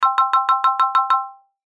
14. Начисление выигрыша автоматом